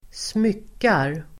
Uttal: [²sm'yk:ar]